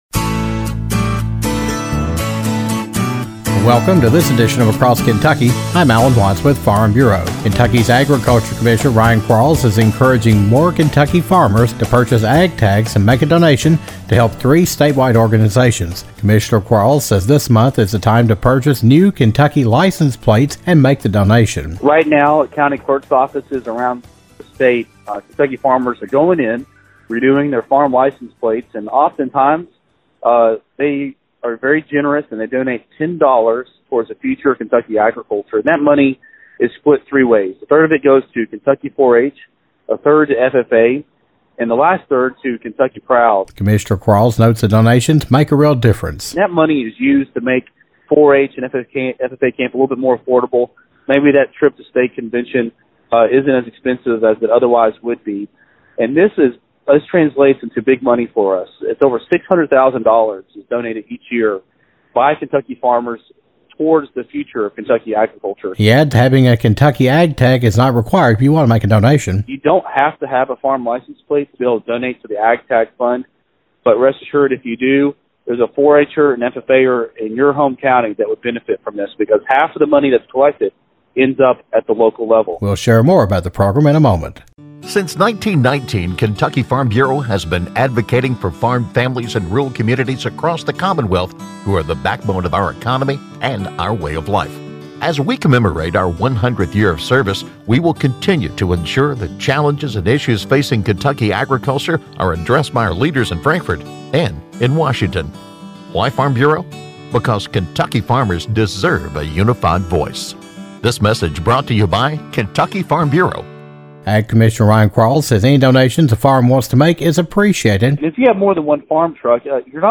The Kentucky Ag Tag Program raised more than $600,000 in 2018 to help Kentucky 4-H and FFA members, and the Kentucky Proud Program thanks to farmers donating an extra $10 when renewing licenses for the year.  Kentucky Agriculture Commissioner Ryan Quarles talks about the importance of the program, how farmers can participate and what their participation will ultimately do.